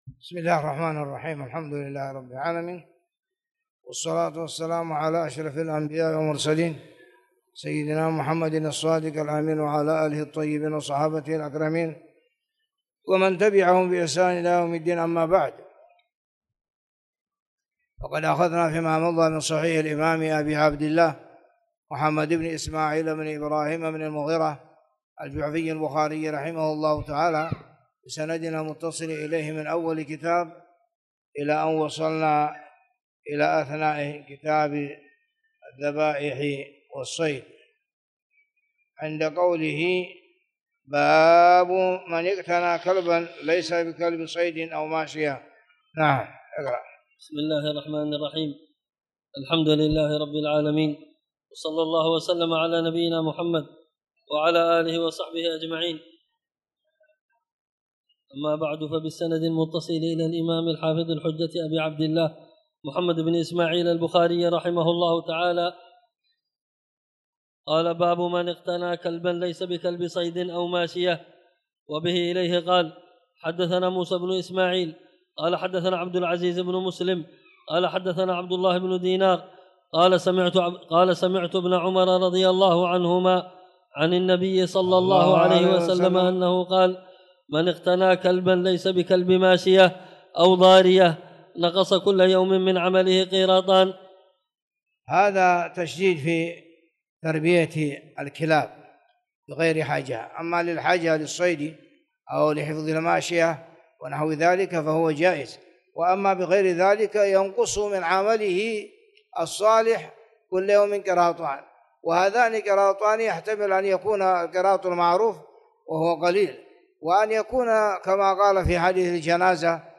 تاريخ النشر ١٣ صفر ١٤٣٨ هـ المكان: المسجد الحرام الشيخ